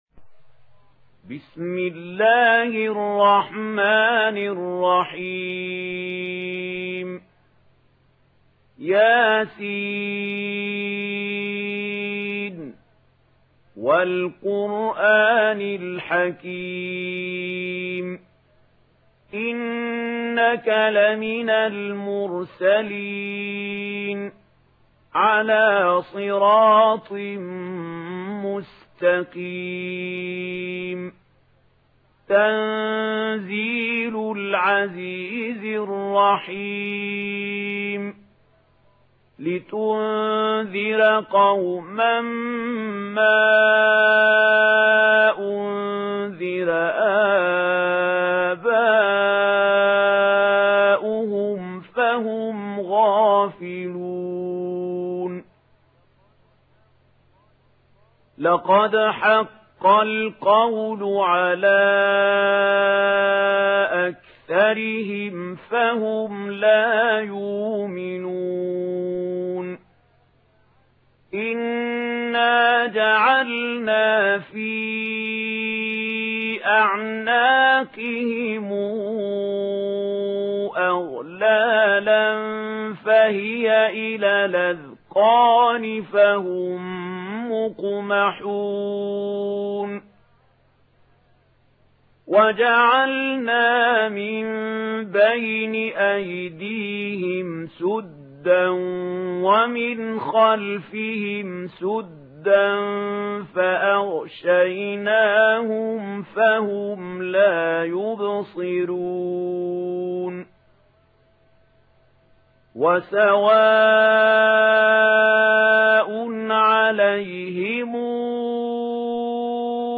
Yasin Suresi İndir mp3 Mahmoud Khalil Al Hussary Riwayat Warsh an Nafi, Kurani indirin ve mp3 tam doğrudan bağlantılar dinle